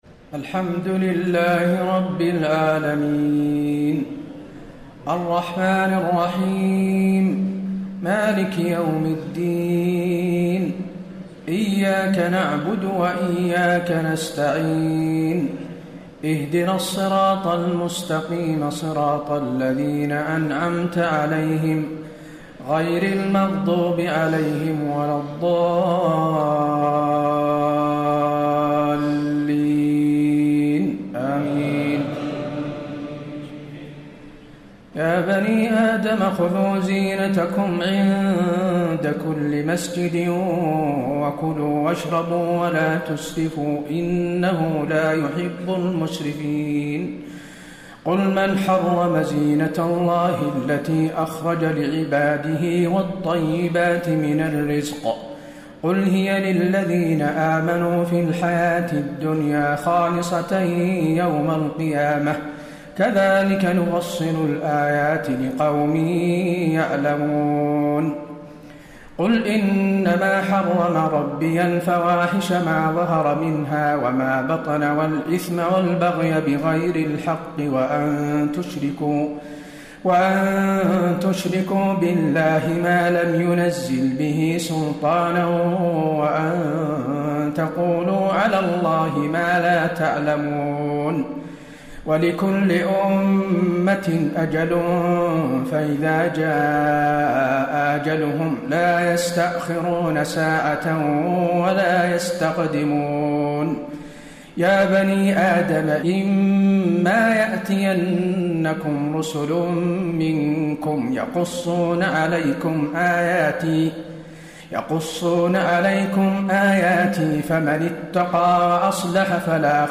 تهجد ليلة 28 رمضان 1434هـ من سورة الأعراف (31-84) Tahajjud 28 st night Ramadan 1434H from Surah Al-A’raf > تراويح الحرم النبوي عام 1434 🕌 > التراويح - تلاوات الحرمين